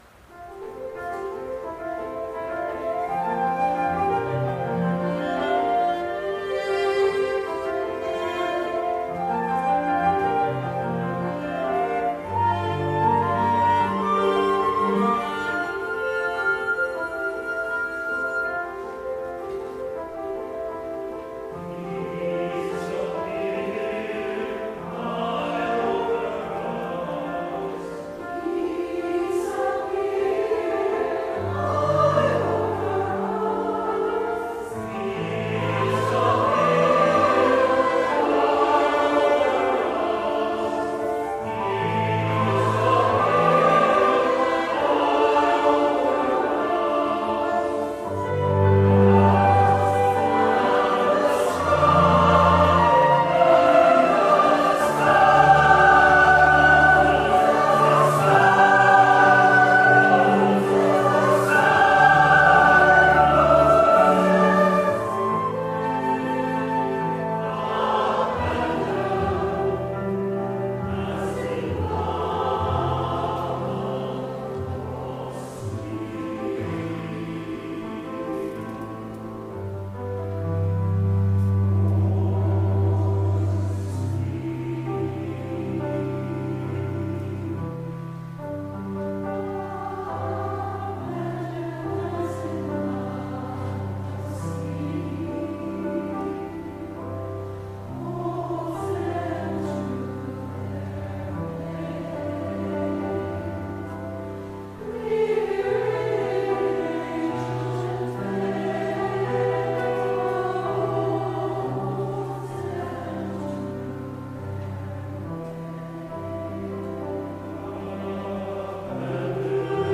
Voicing: SATB
Instrumentation: piano, flute and cello